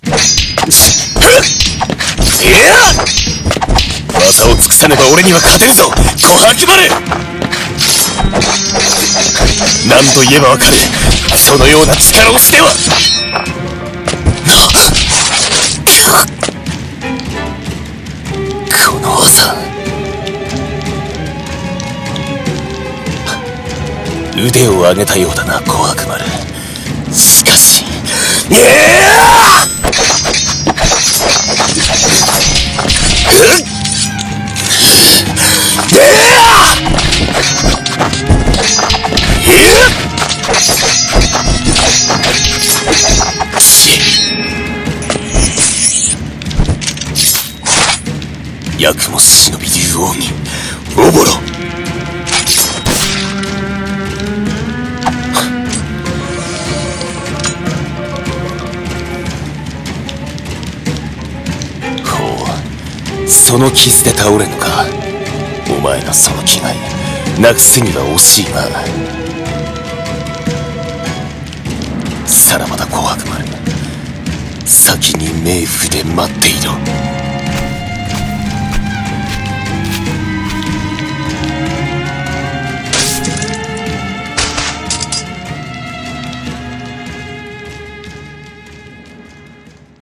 和風/忍者/戦闘/声劇台本